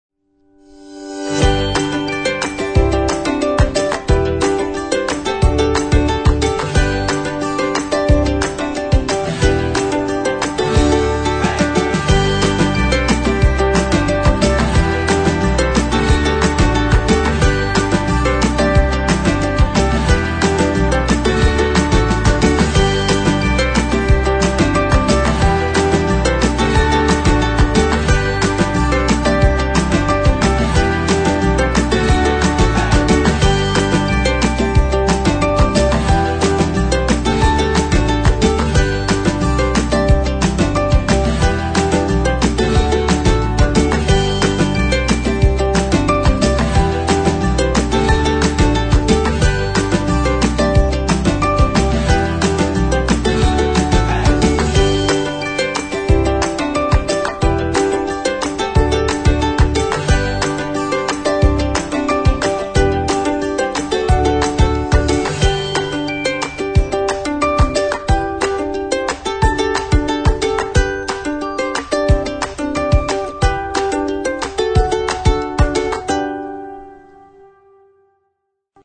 描述：这是一个乐观有趣的原声音乐与曼陀林，尤克里里和原声吉他。 随着歌曲的进行，乐器也在不断地增加。
以原声吉他，钢琴，尤克里里琴，铃声，哈蒙德和主唱为特色。